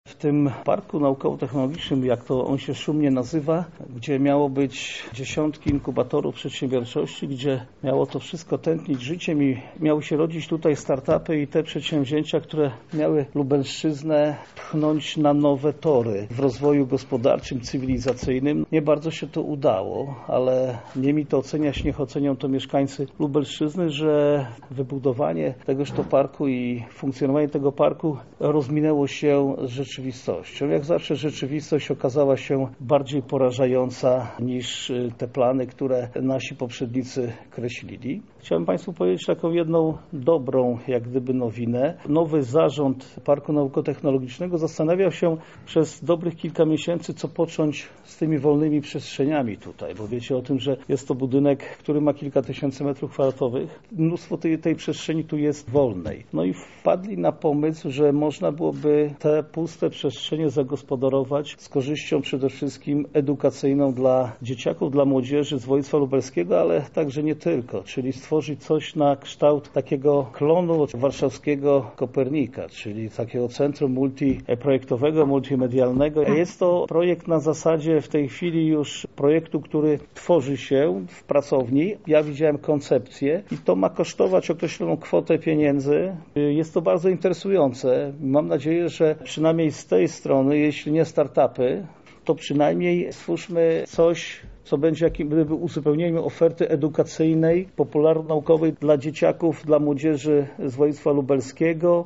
Na konferencji zorganizowanej o godzinie 12 przedstawione koncepcje utworzenia w Lublinie czegoś, co niejednemu przypomni warszawskie Centrum Nauki Kopernik.
Ostatnie lata działalności obiektu podsumował sam marszałek województwa lubelskiego, Jarosław Stawiarski: